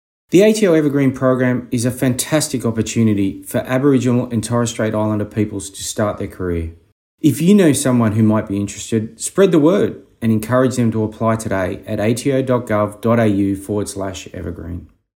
The following recordings feature ATO Deputy Commissioner and Indigenous Champion, Matthew Hay, discussing the 2022 Evergreen program.